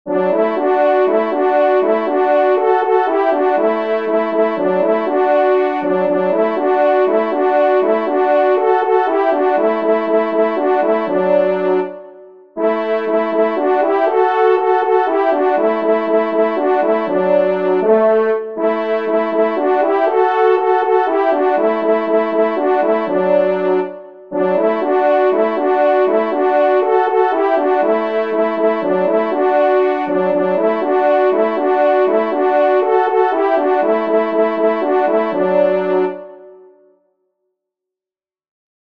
Musique Synthé “French Horns” (Tonalité de Ré